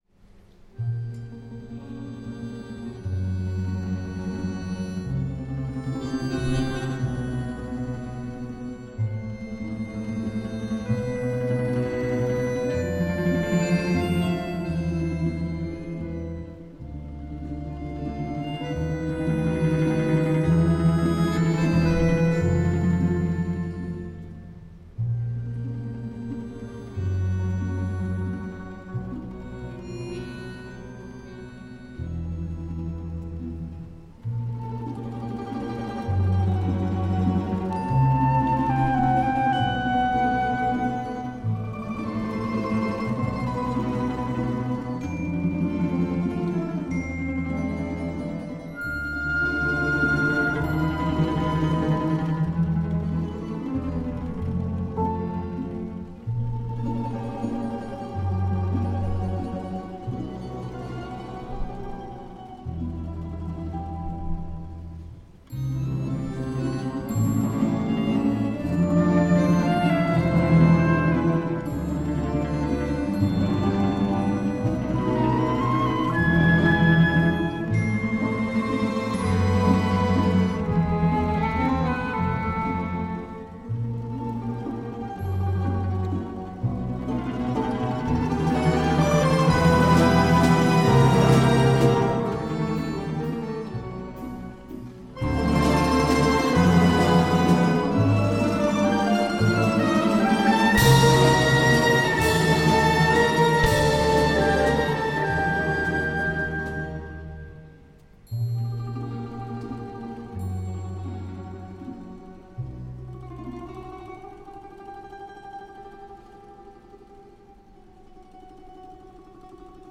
Образ ушедшей эпохи, оставшийся лишь в воспоминаниях...